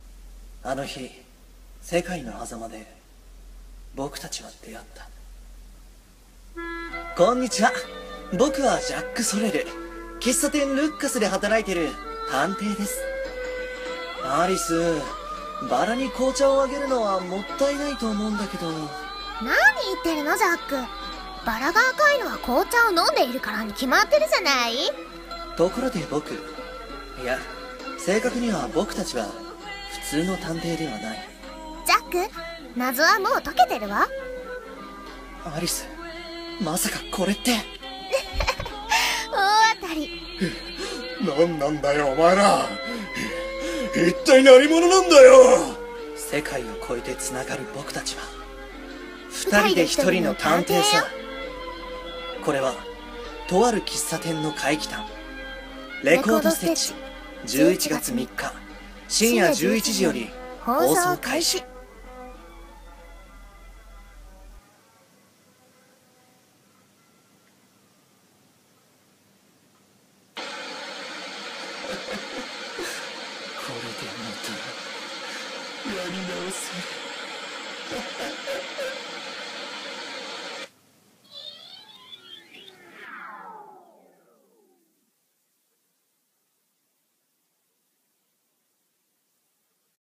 【声劇台本】偽アニメ予告CM 「レコード・ステッチ」